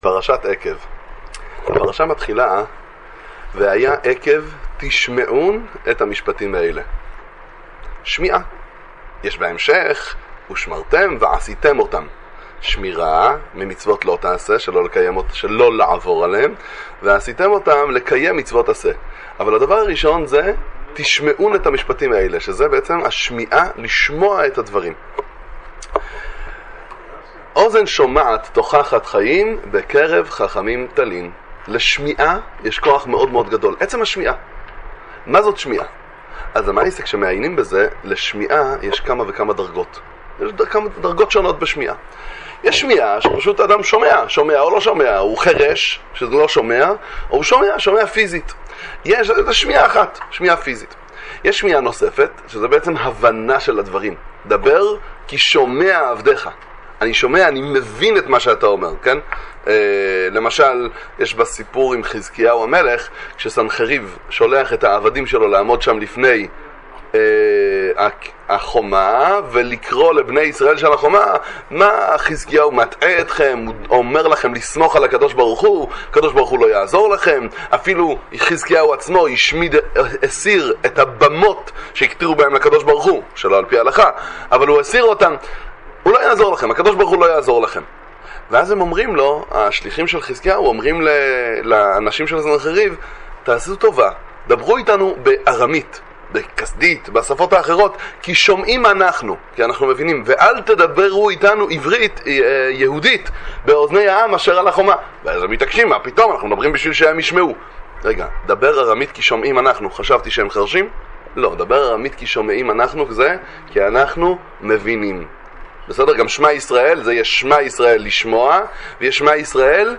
דבר תורה קצר לפרשת השבוע